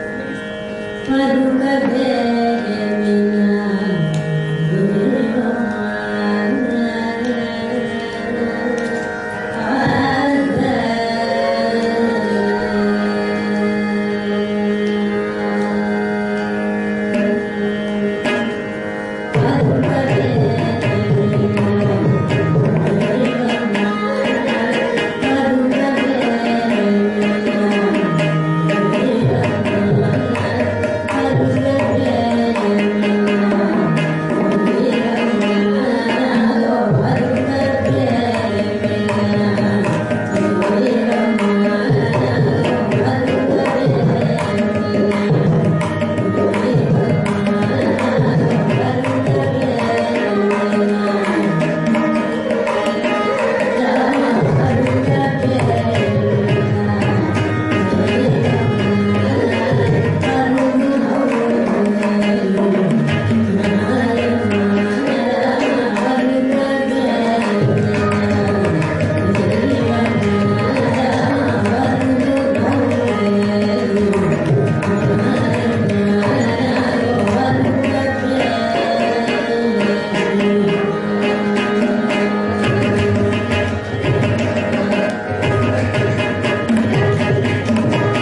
carnatic music